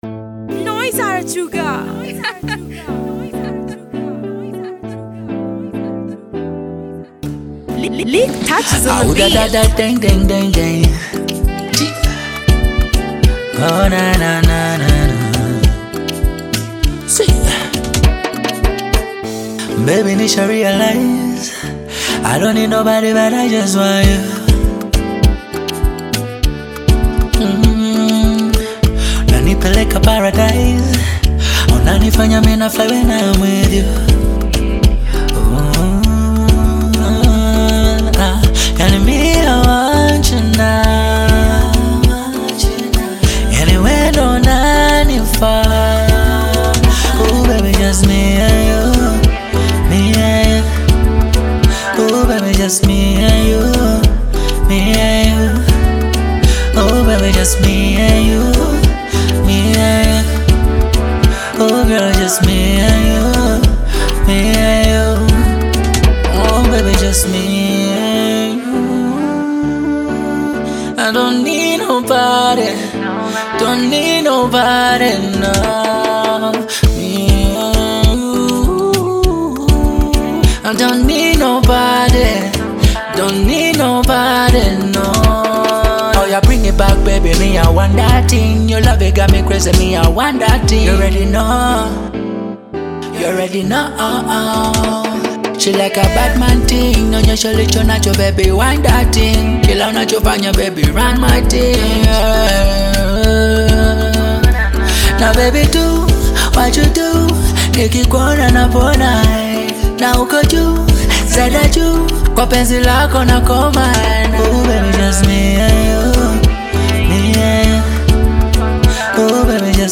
Tanzanian Bongo Flava artist, singer and songwriter
Bongo Flava